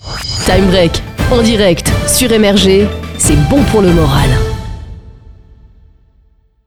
• Voix féminine / urbaine
SWEEP_-_CEST_BON_POUR_LE_MORAL.wav